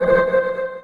sci-fi_radar_ui_scan_01.wav